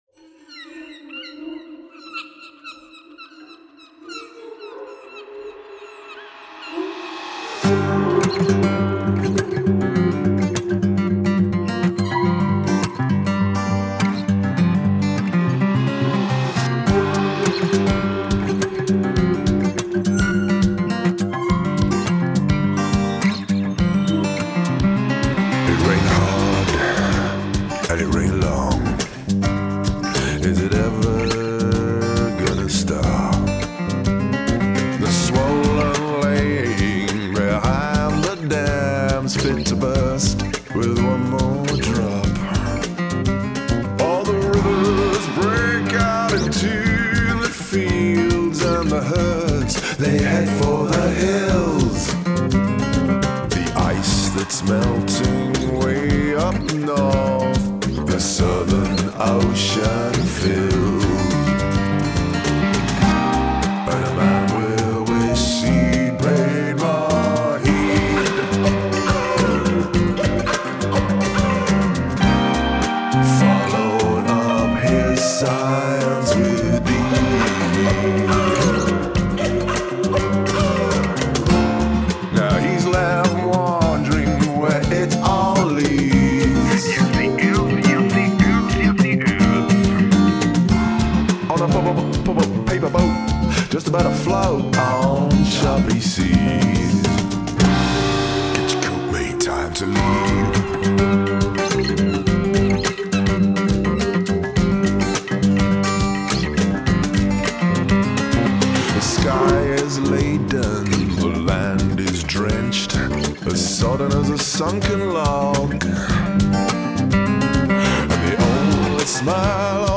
Acoustic Guitars,Electronica,
SFX & Voices
Cajon & Assorted Percussion